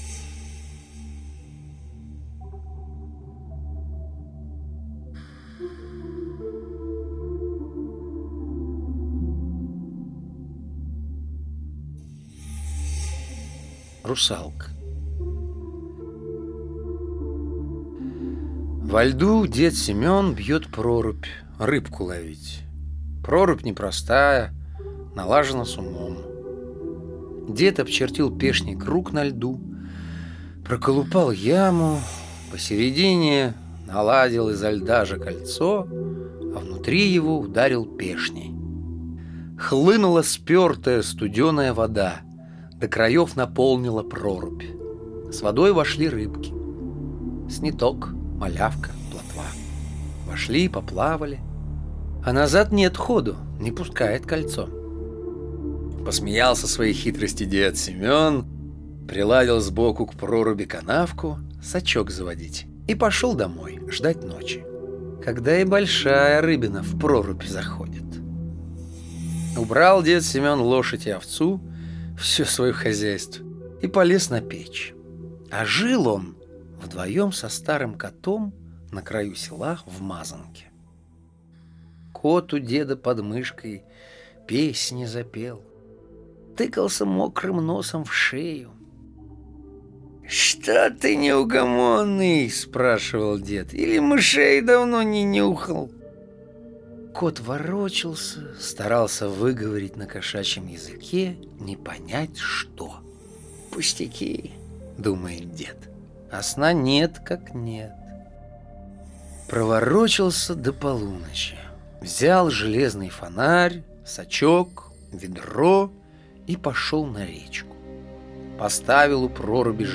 Русалка - аудиосказка Алексея Толстого - слушать онлайн